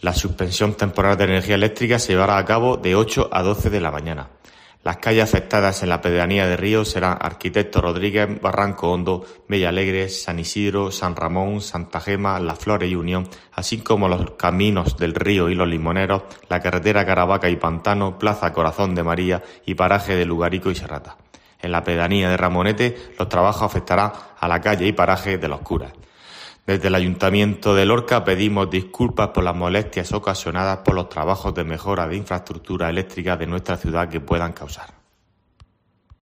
Antonio David Sánchez, concejal Ayto Lorca